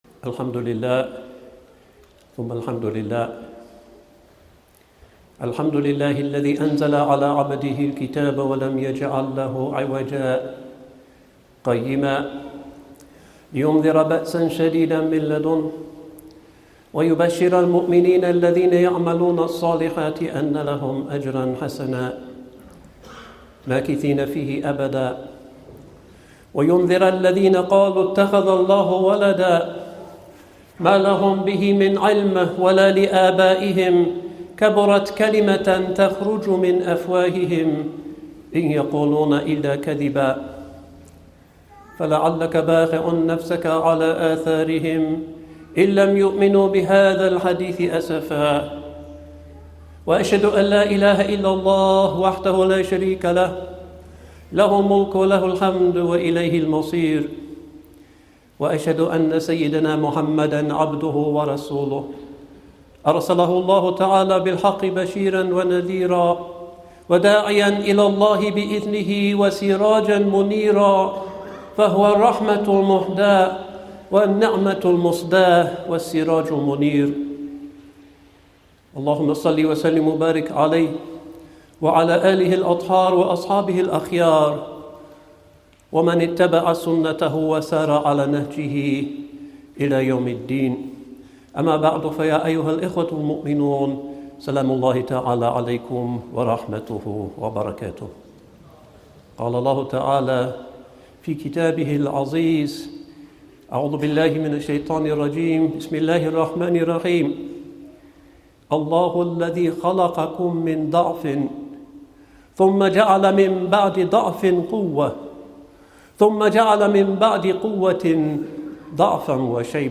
Age & Wisdom – Abdal Hakim Murad： Friday Sermon.mp3